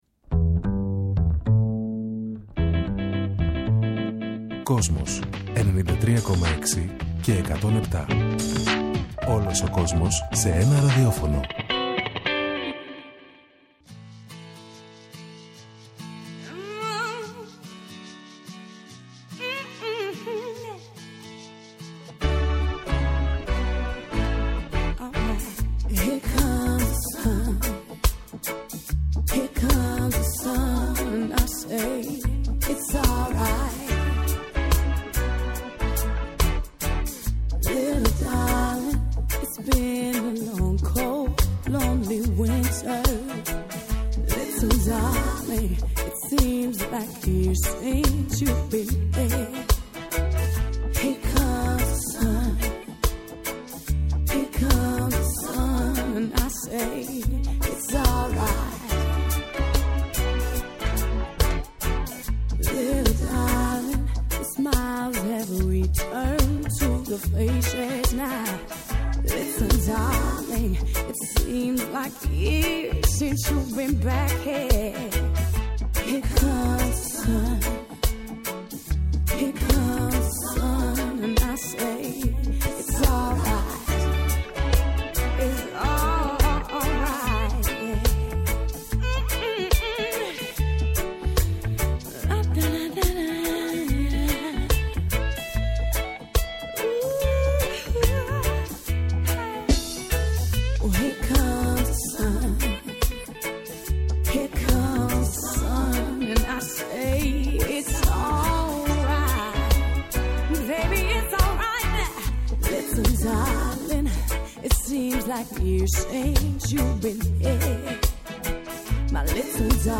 Οι μουσικές από τη δεκαετία του ’60 μέχρι και τις μέρες μας
μουσικό οδοιπορικό